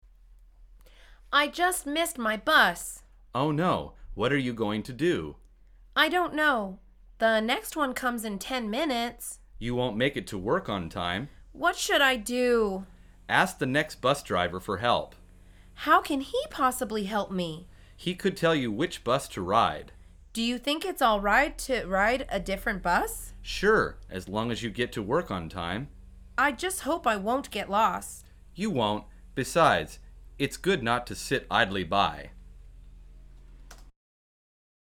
مجموعه مکالمات ساده و آسان انگلیسی – درس شماره سوم از فصل سوار شدن به اتوبوس: مسیر اتوبوس جایگزین